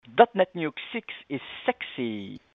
sounds much better with his French accent ).Thank you for your support.